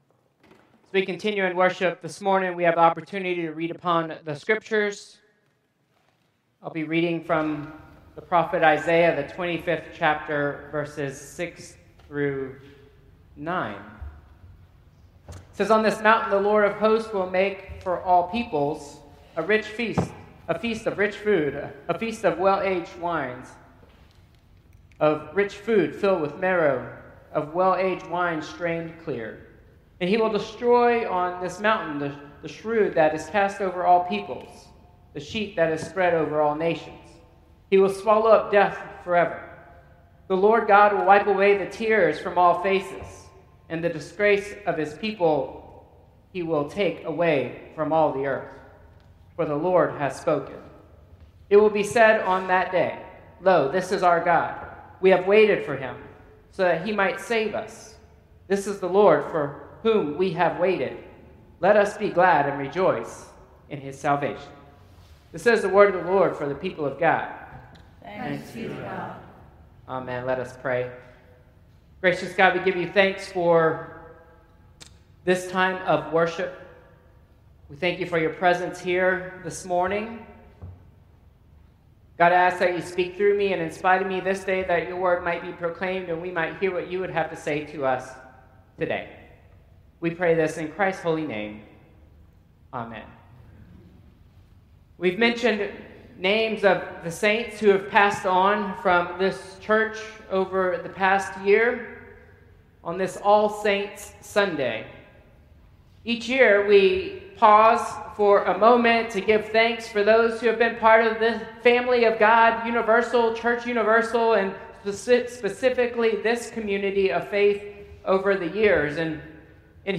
Traditional Service 11/2/2025